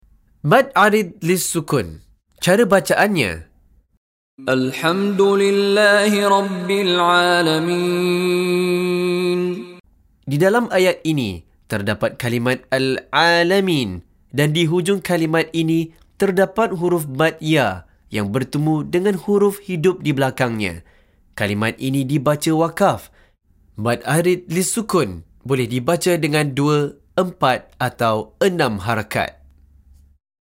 Penerangan Hukum + Contoh Bacaan dari Sheikh Mishary Rashid Al-Afasy